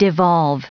Prononciation du mot devolve en anglais (fichier audio)